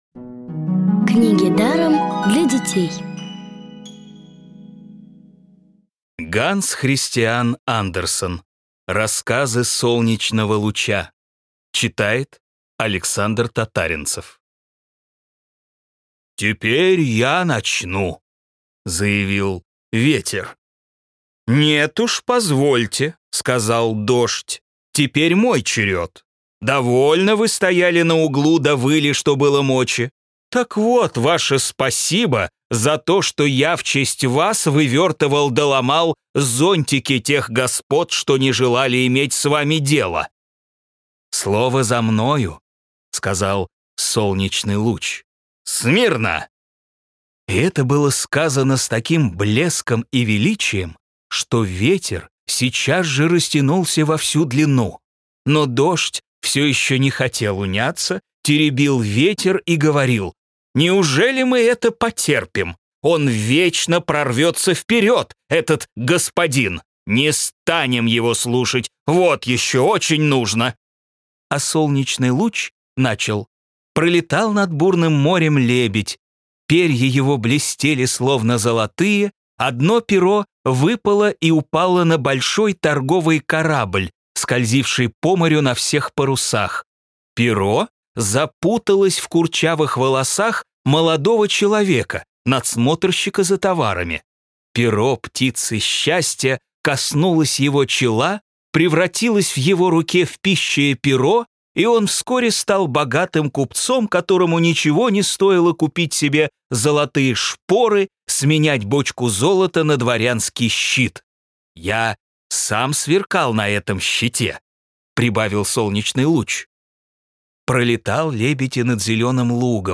Аудиокниги онлайн – слушайте «Рассказы солнечного луча» в профессиональной озвучке и с качественным звуком.